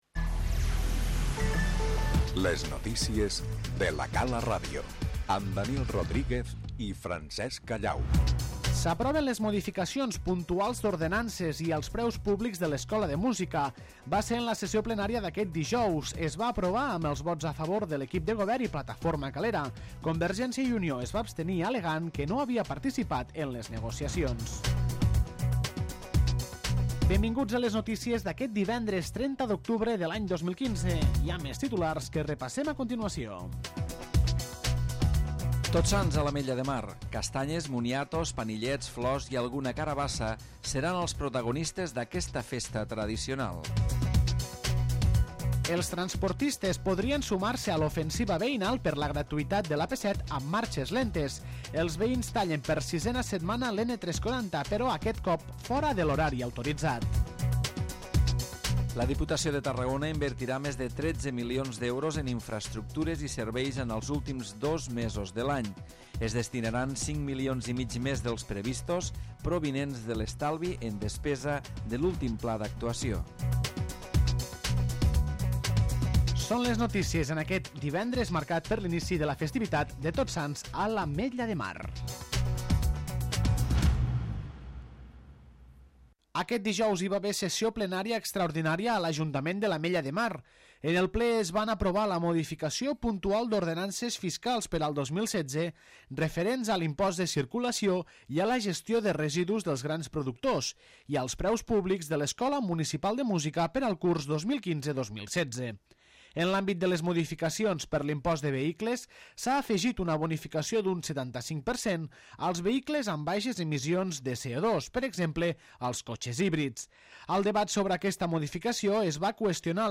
La sessió plenària extraordinària de dijous i la celebració de Tots Sants, encapçalen l'informatiu local de La Cala Ràdio d'aquest divendres.